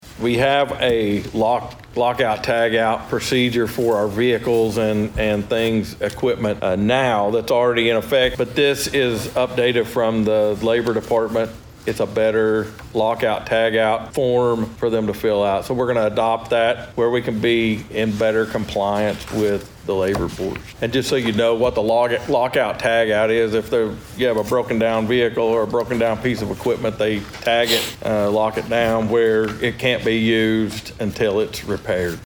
District Two Commissioner Steve Talburt provides more details.